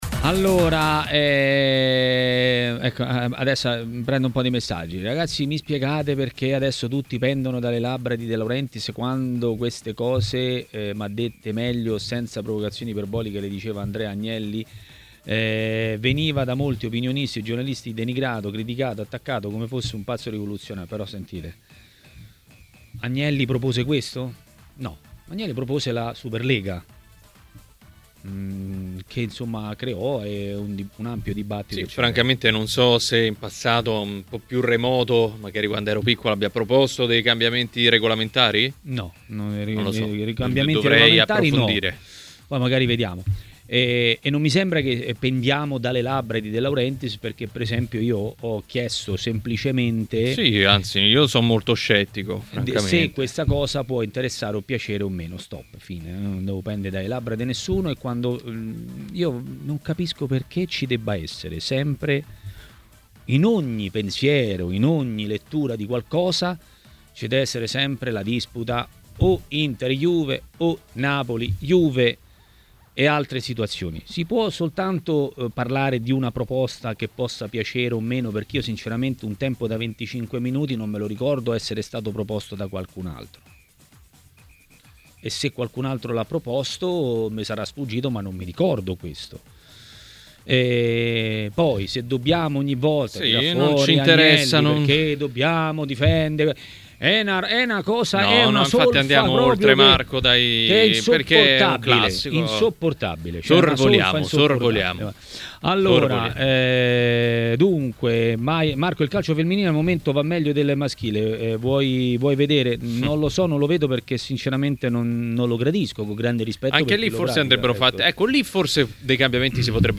A Maracanà, nel pomeriggio di TMW Radio,